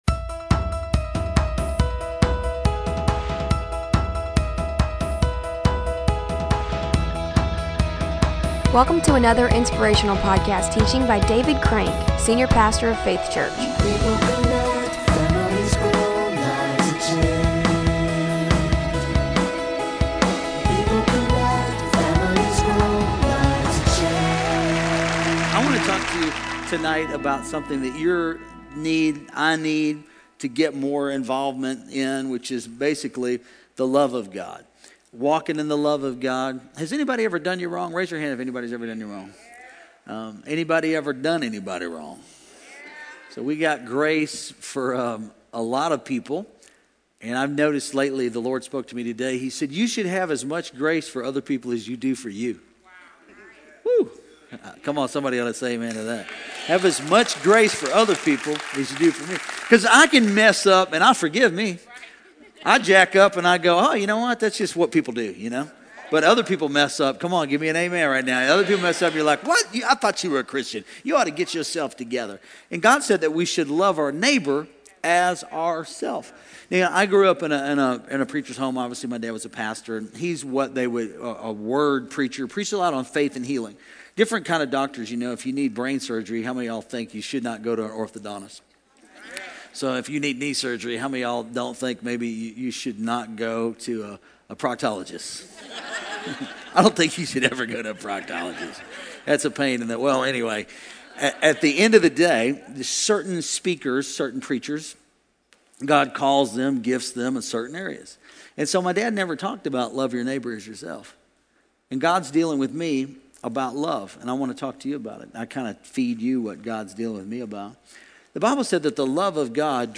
Throughout this sermon